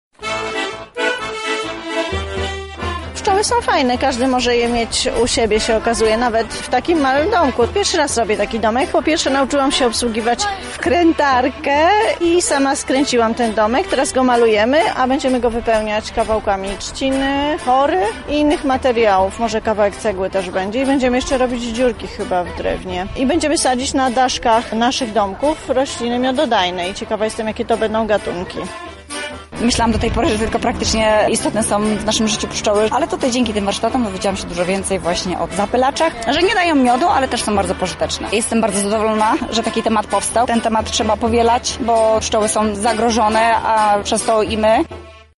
Uczestnicy opowiedzieli jak wyglądały poszczególne etapy pracy, a także chwalili wybór tematu wydarzenia.